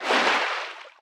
Sfx_creature_babypenguin_swim_glide_02.ogg